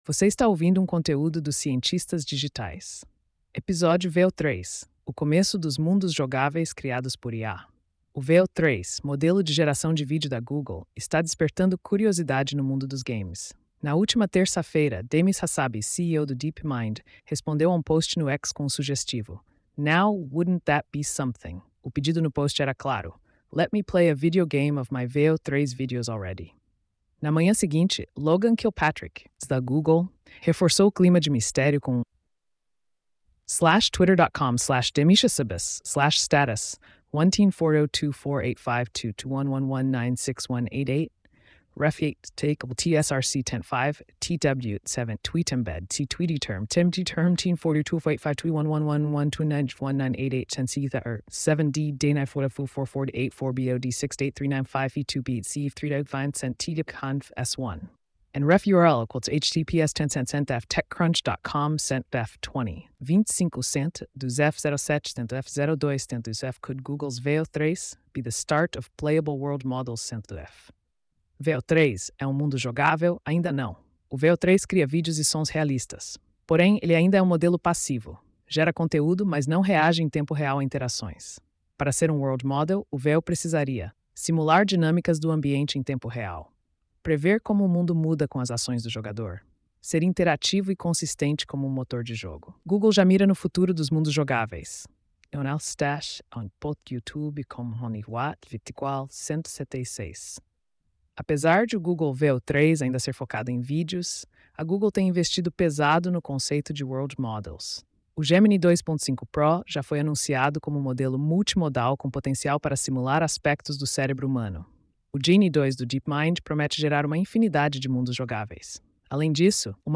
post-3290-tts.mp3